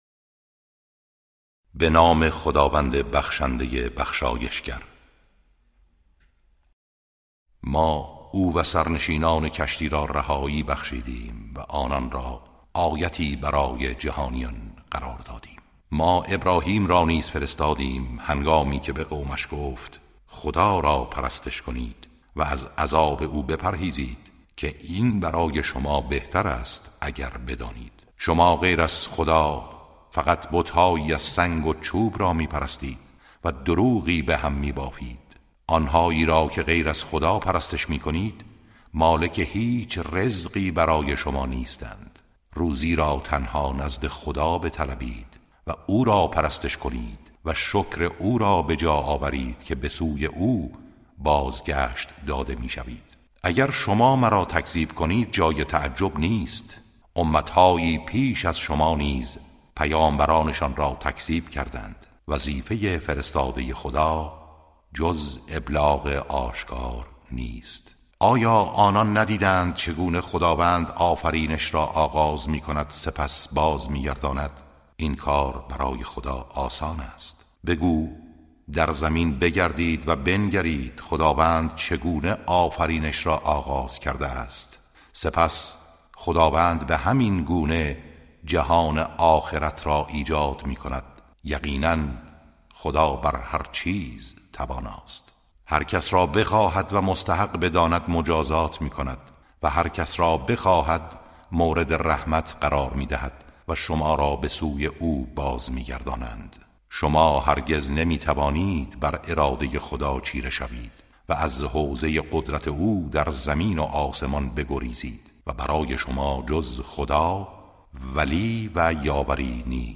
ترتیل صفحه ۳۹۸ سوره مبارکه عنکبوت (جزء بیستم)
ترتیل سوره(عنکبوت)